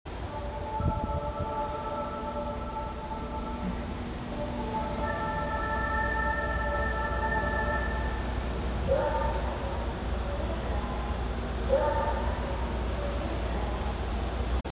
・2000系車載メロディ（〜2023/3頃）